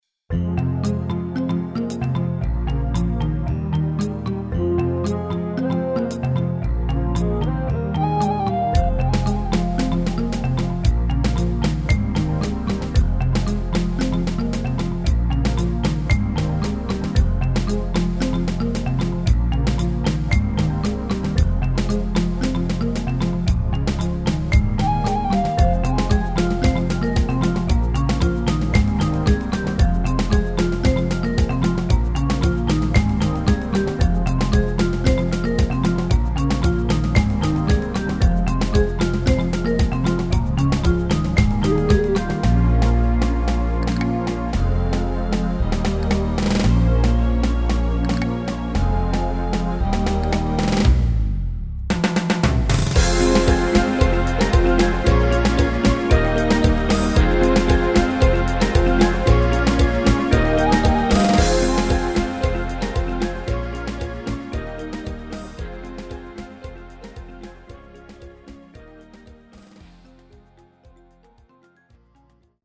Basic Mp3-Playback (ohne VH-Chor) auf Basis von Roland VSC
Kategorie: Basic-MP3-Playback
Genre(s): Deutschpop  |  Rhythmus-Style: Samba
Reines Playback, ohne Melodie, ohne Chorstimmen